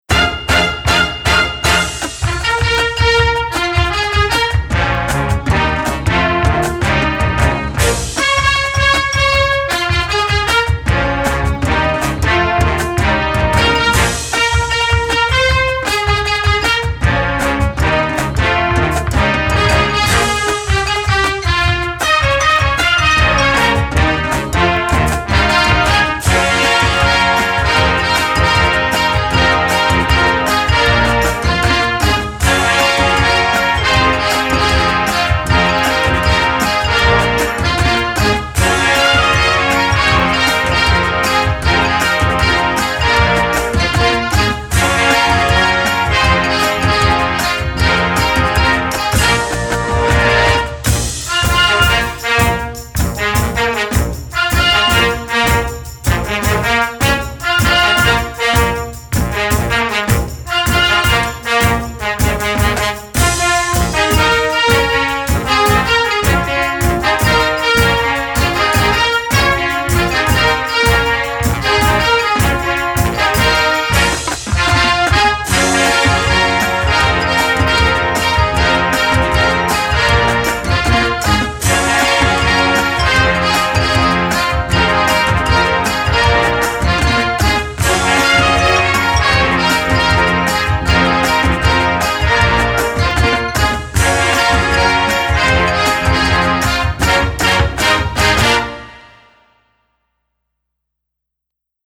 Marching-Band
Besetzung: Blasorchester